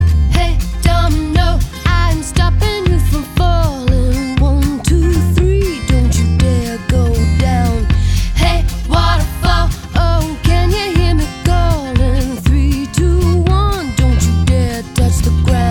Музыка из рекламы